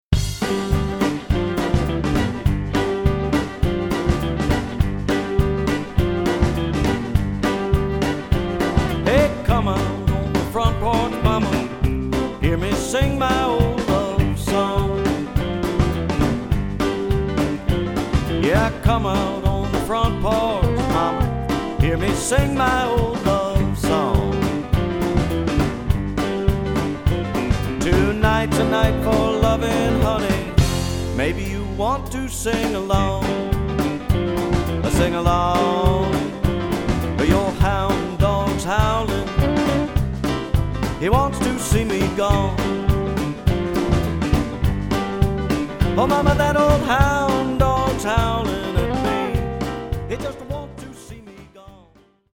kicks up its collective heels
American roots music